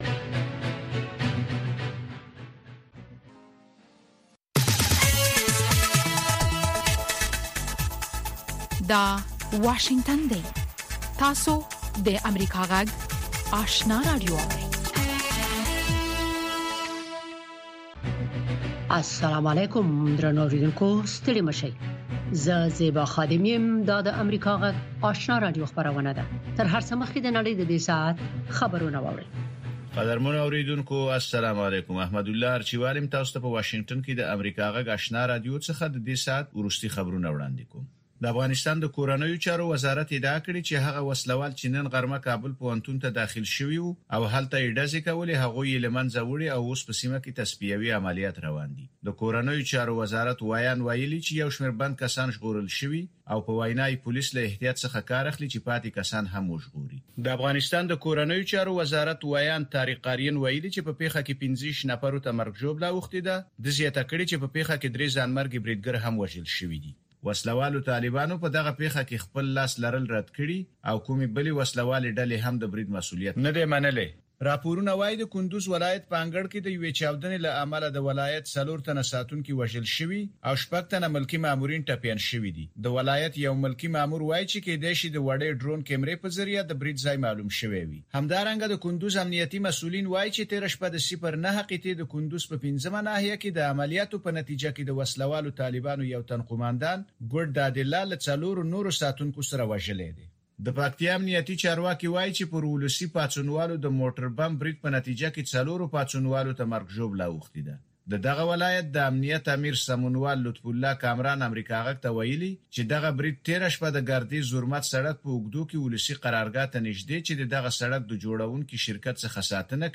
دوهمه ماښامنۍ خبري خپرونه